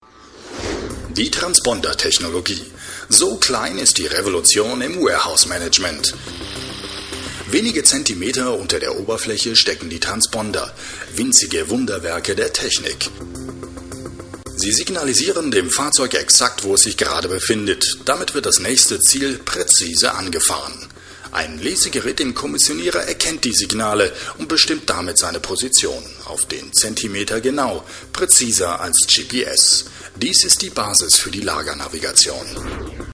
Overvoice, Off Sprecher, Hörspiel, Hörbuch, Imagefilm, Werbung
Sprechprobe: eLearning (Muttersprache):